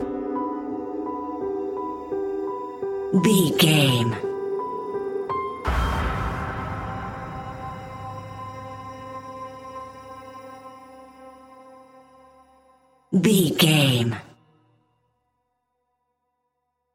Aeolian/Minor
Slow
ominous
suspense
eerie
piano
synthesiser
horror music
Horror Pads